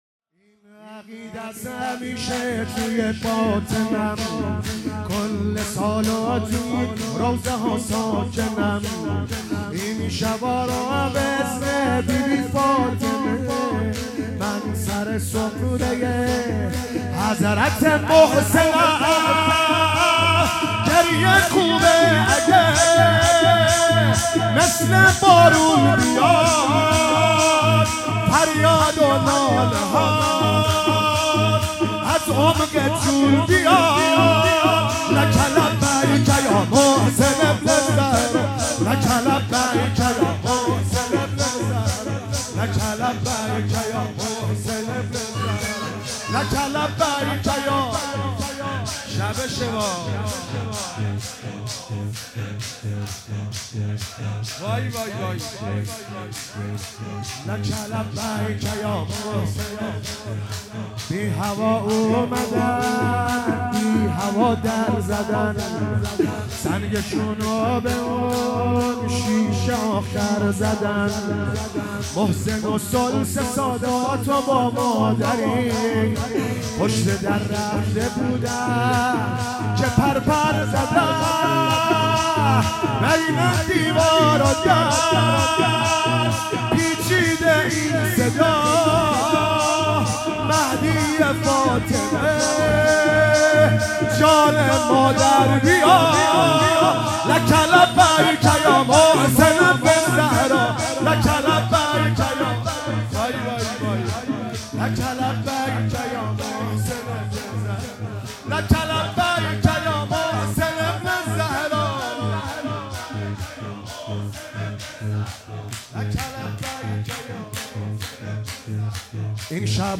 فاطمیه 97
شور